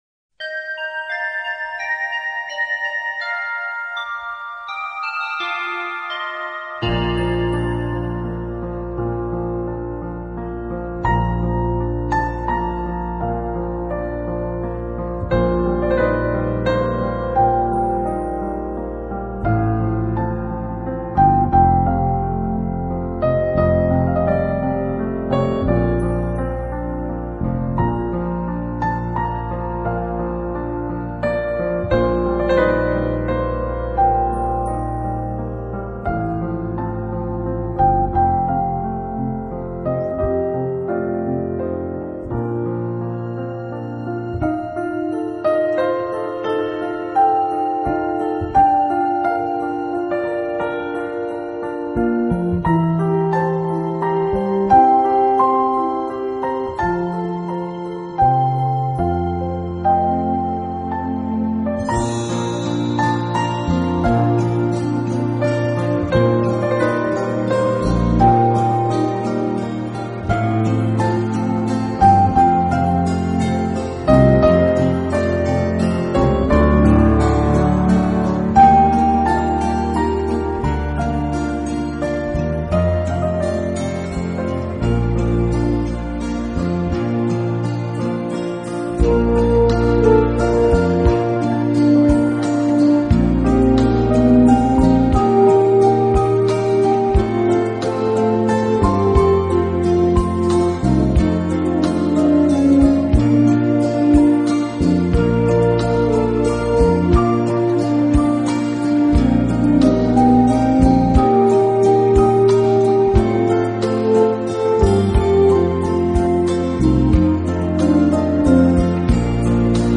云上的琴声在流动着、飘舞着、涌动着，涌动着一种温柔的细腻，一丝甜柔的
以钢琴为主，再配以架子鼓、长笛、电子吉他，奏出了天堂意象的美幻天籟之音。